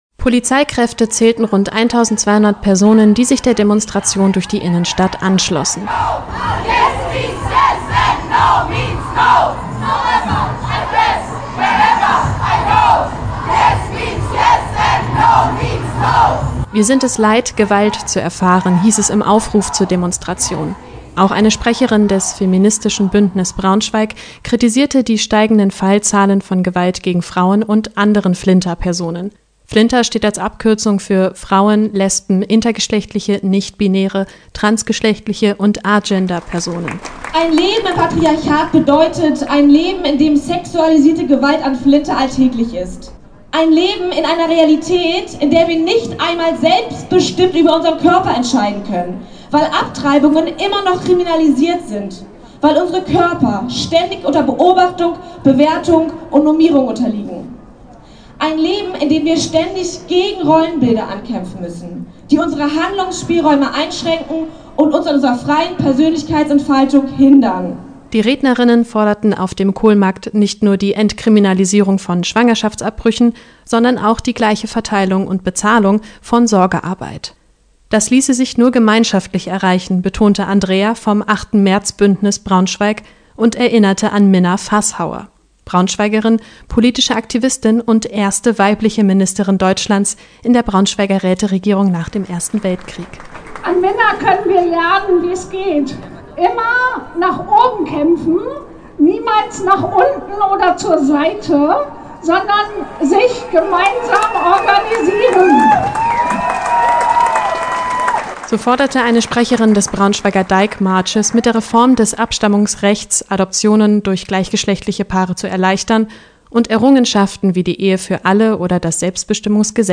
„Ende der Bescheidenheit“: Demonstration zum Weltfrauentag in Braunschweig - Okerwelle 104.6